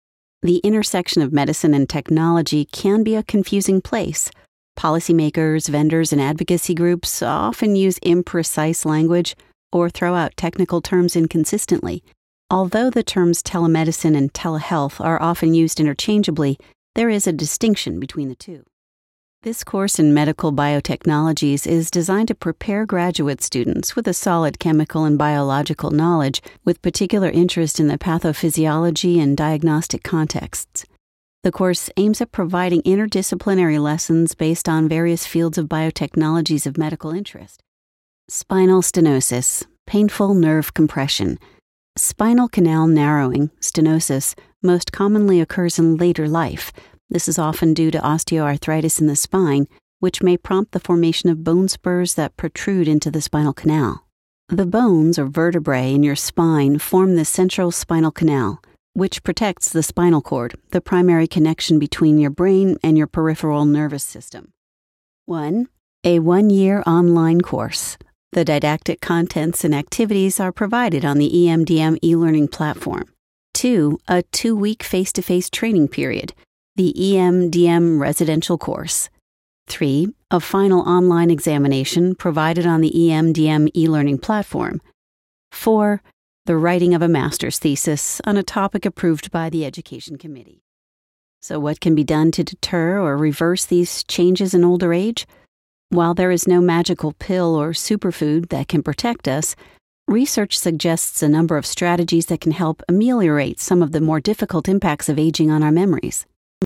Warm, Dynamic, Versatile, Textured voice. Female voice actor. American.
Sprechprobe: eLearning (Muttersprache):